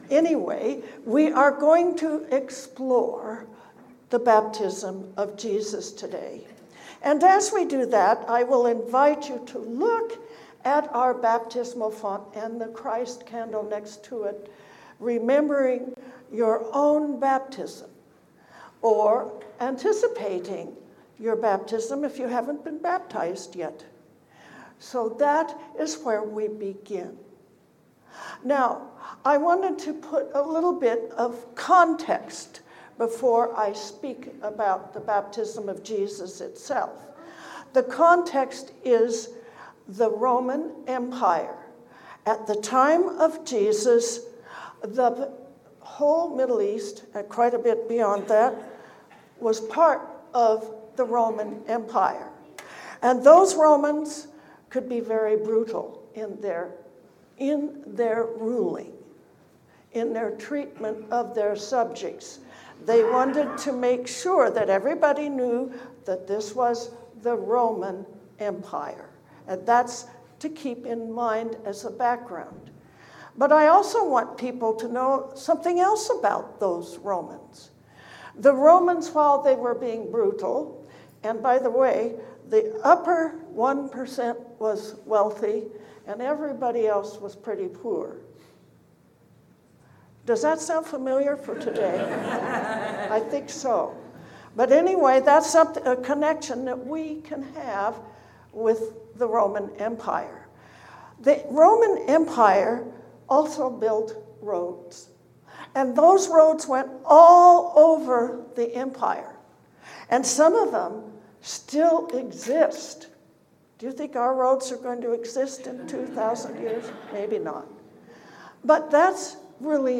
Baptized for Life. A sermon on the Feast of the Baptism of Jesus.